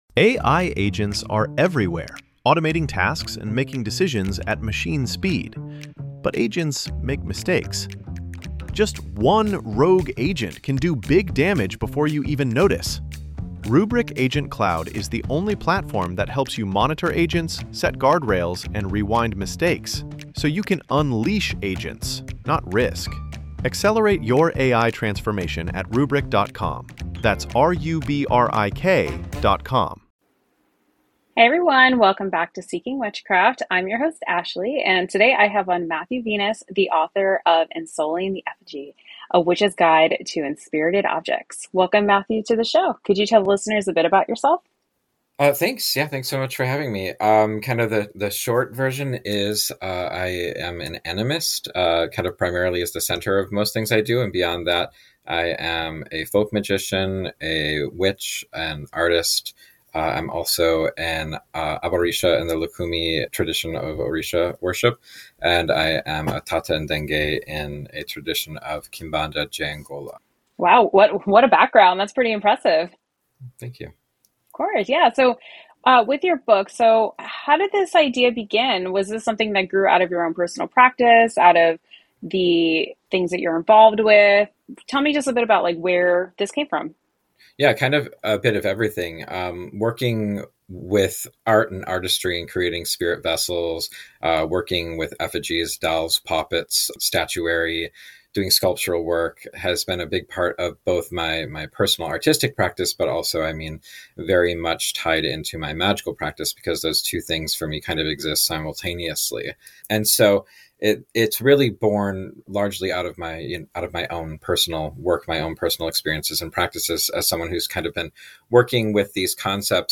From crafting effigies and spirit houses to building authentic relationships with spirits, this conversation offers both practical techniques and deeper insights for anyone looking to expand their magical toolkit and spiritual connections.